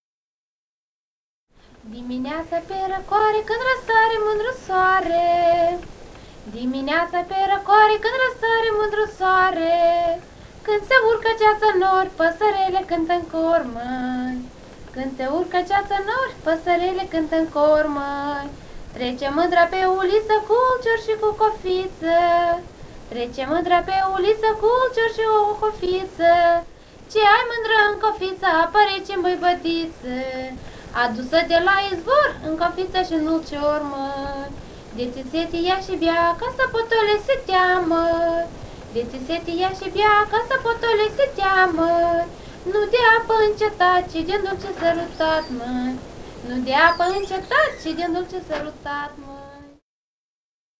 Этномузыкологические исследования и полевые материалы
«Dimineata pe racoare» [Рано утром на прохладе] (румынская).